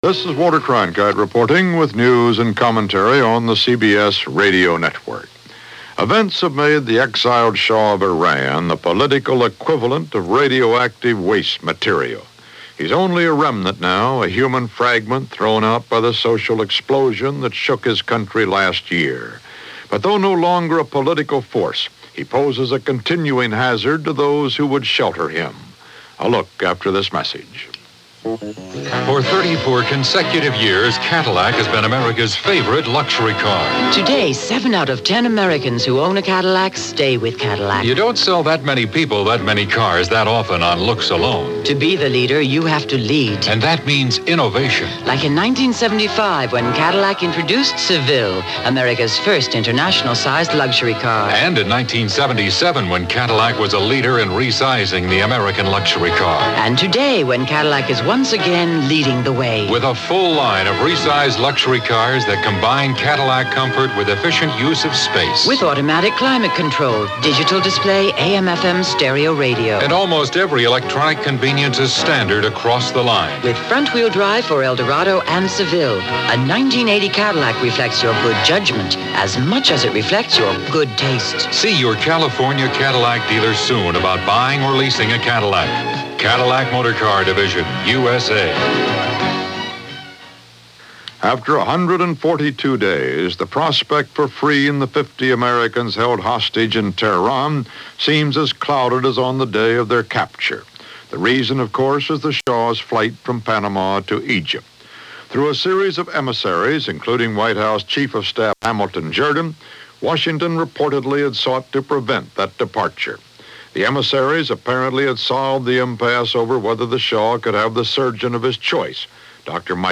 March 24, 1980 – CBS World News Roundup – Walter Cronkite News and Comment